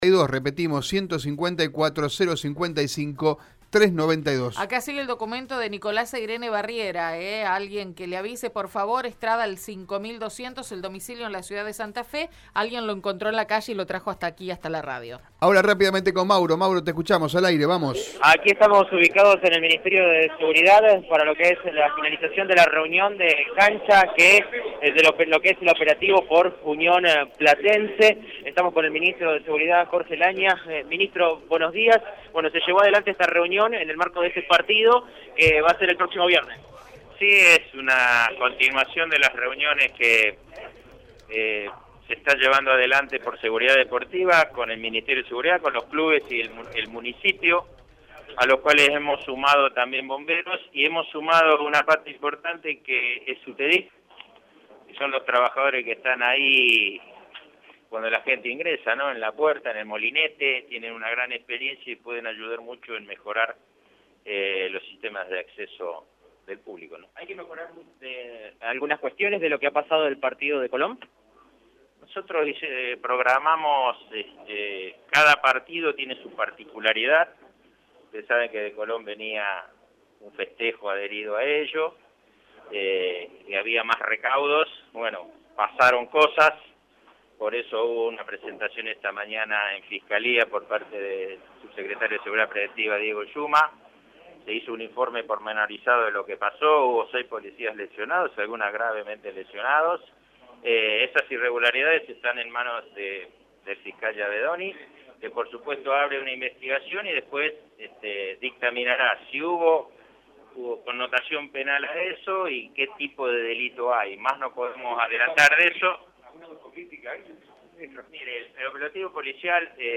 «Va haber 300 efectivos policiales, como si fuera un partido a cancha llena. Hay que controlar distintos aspectos como la Tribuna Segura y la vacuna» comentó Lagna en conferencia de prensa sobre el operativo.